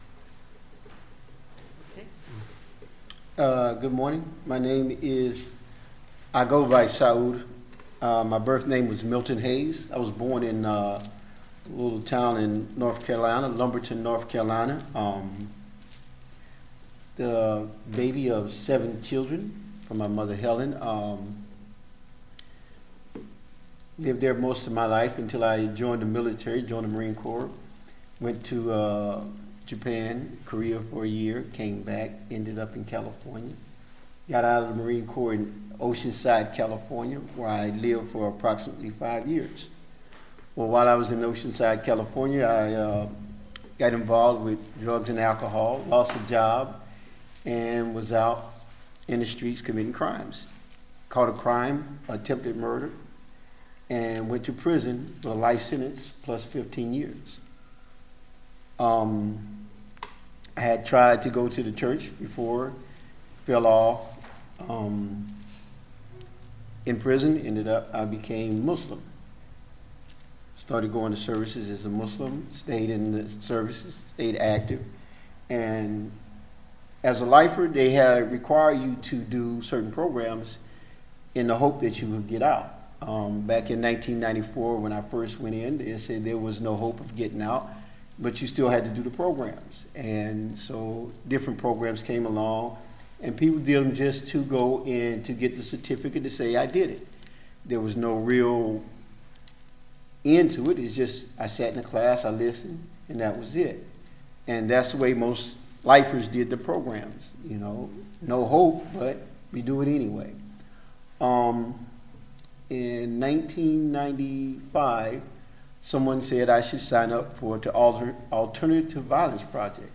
Listen to the most recent message from Sunday worship at Berkeley Friends Church.